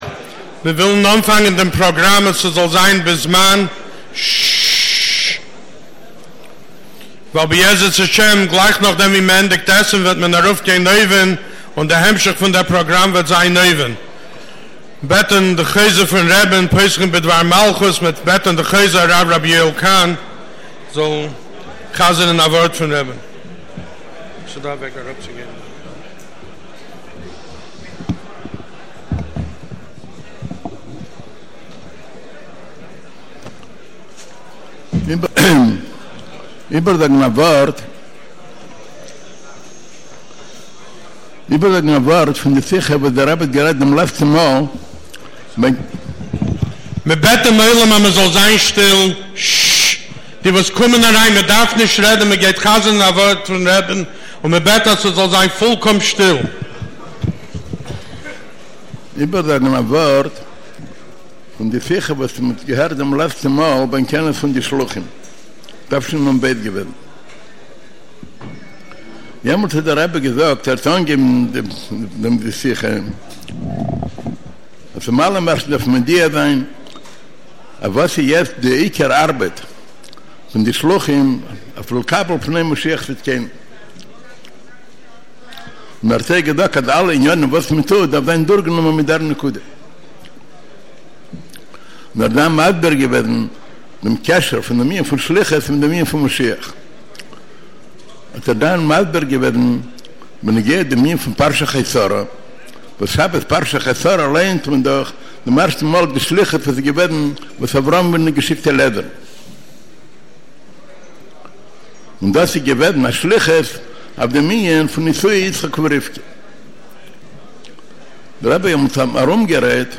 מלווה-מלכה בכינוס חולק לשני אירועים נפרדים